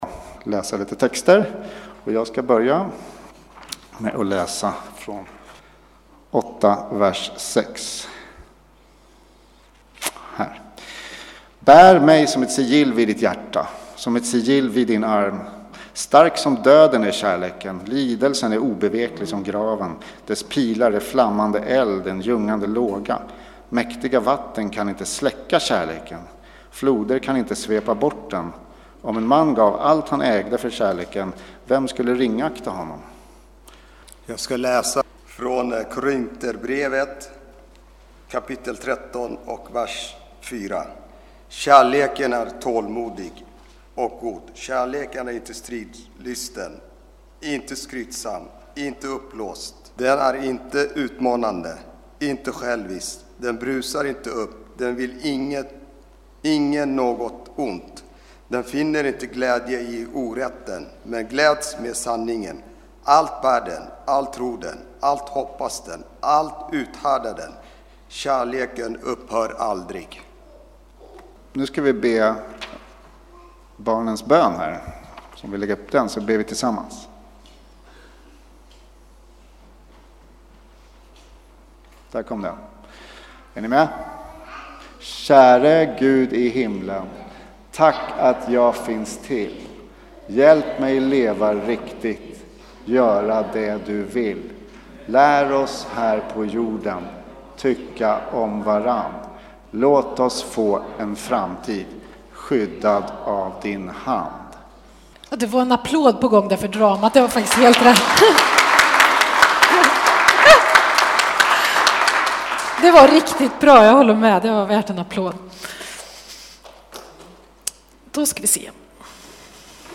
Från gudstjänst med alla åldrar